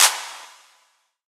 DDW Clap 2.wav